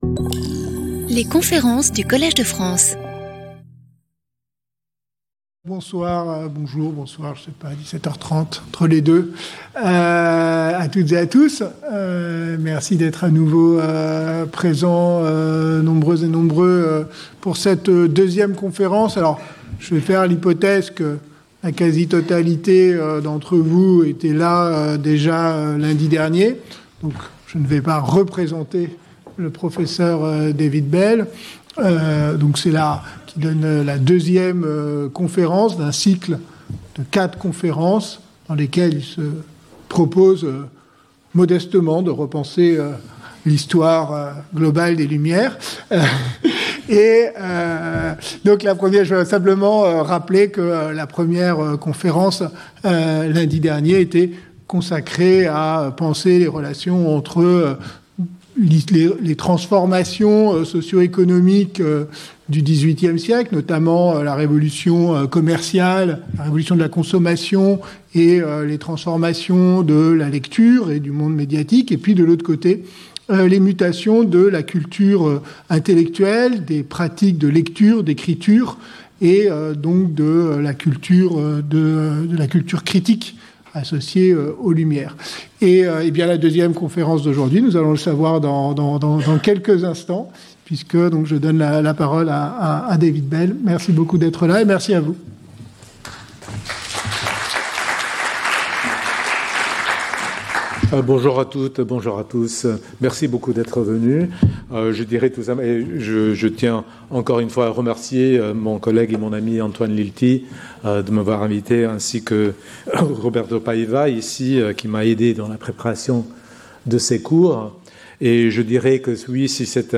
Guest lecturer